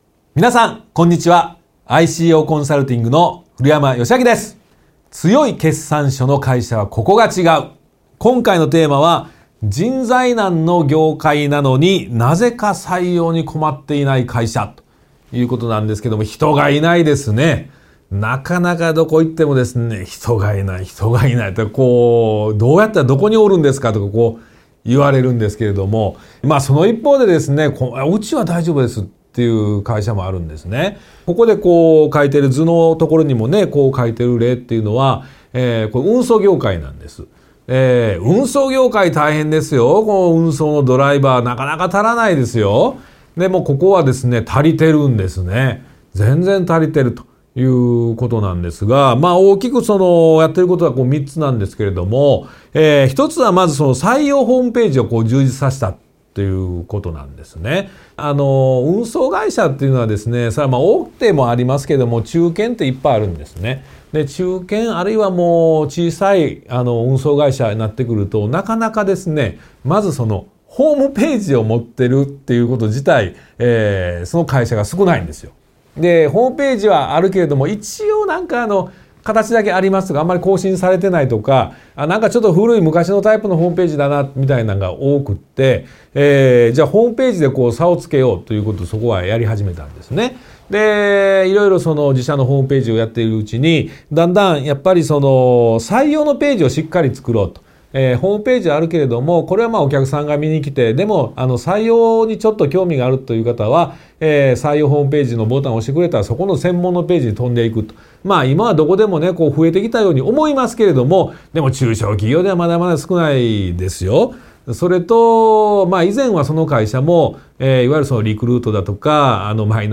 ワンポイント音声講座↑音声講座は上記を再生ください↑ 今回のキーワード「採用ホームページ」「福利厚生の充実」「初任給」 採用の応募者は、候補の会社があればまず、その会社のホームページ...